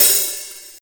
soft-hitnormal1.wav